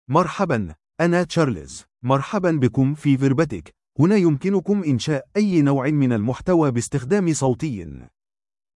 MaleArabic (Standard)
CharlesMale Arabic AI voice
Voice sample
Listen to Charles's male Arabic voice.
Charles delivers clear pronunciation with authentic Standard Arabic intonation, making your content sound professionally produced.